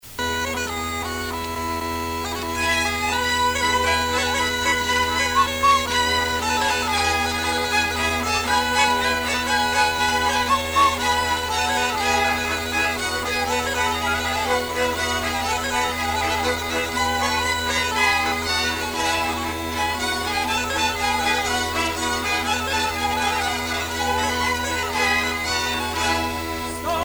Enquête Lacito-CNRS
Pièce musicale inédite